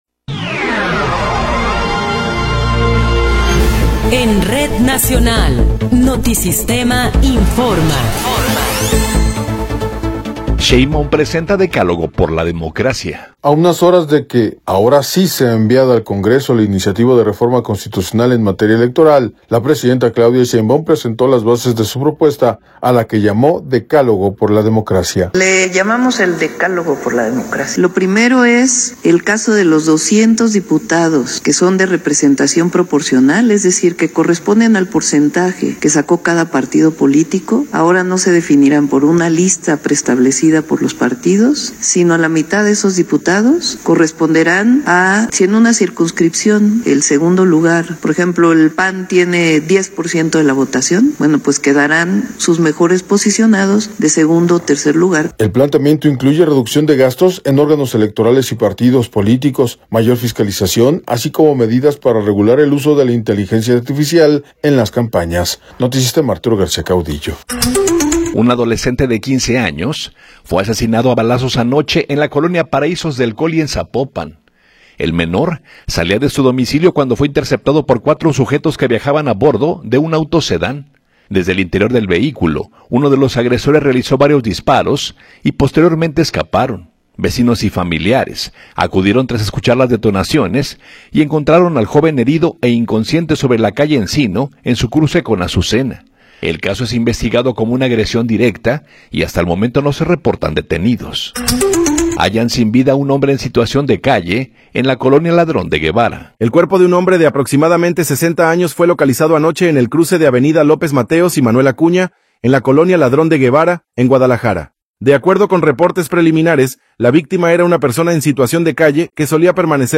Noticiero 10 hrs. – 4 de Marzo de 2026
Resumen informativo Notisistema, la mejor y más completa información cada hora en la hora.